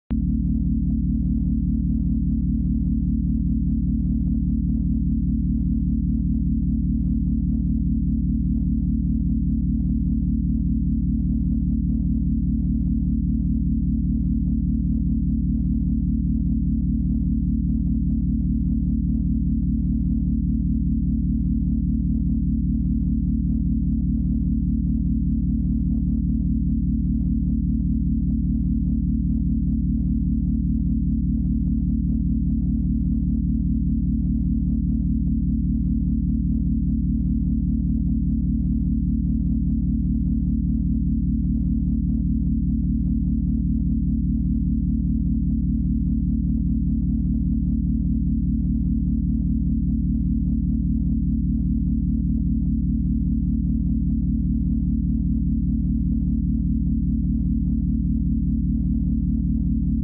Motivational 40 Hz Binaural Beats sound effects free download
Motivational 40 Hz Binaural Beats for Focus